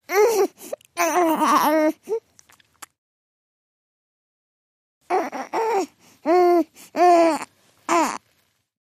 Newborn Baby Strain x2